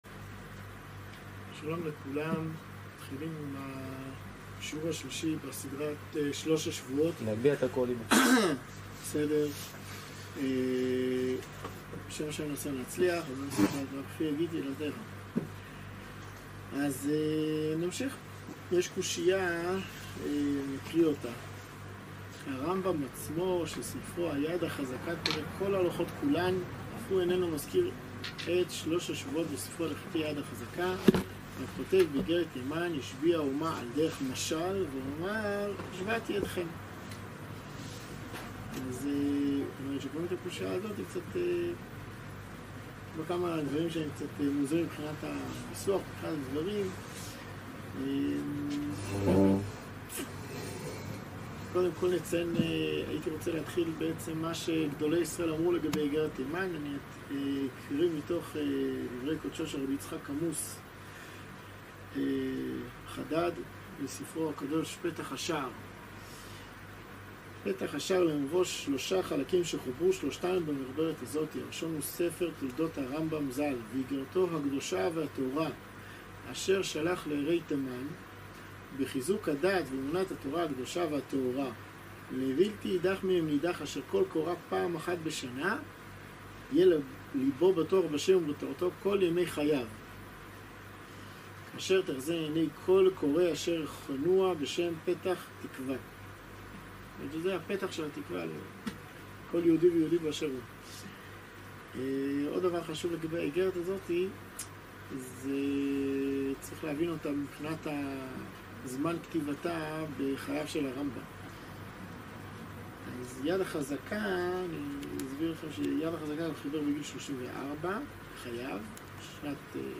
שיעור שלישי - סדרה שלוש השבועות - רמב״ם וטוש״ע